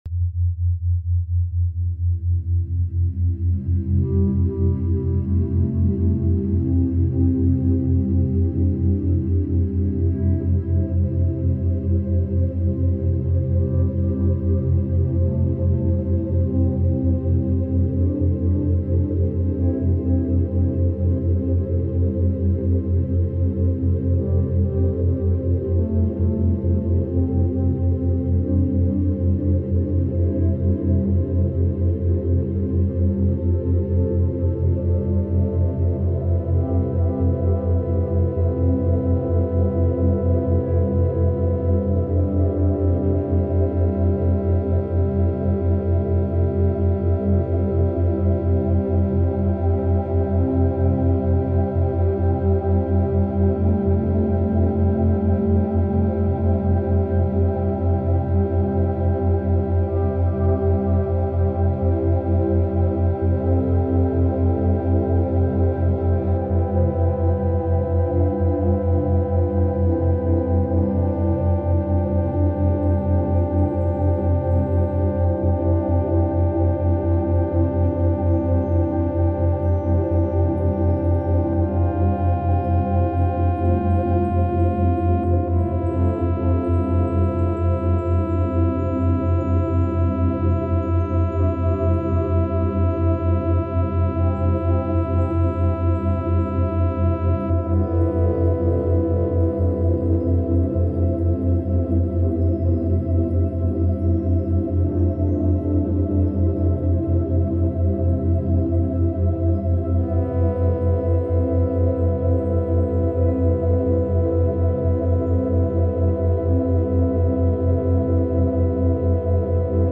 Cette fréquence vibratoire guérit l’inflamation de la prostate
Cette-frequence-vibratoire-guerit-llinflamation-de-la-prostate.mp3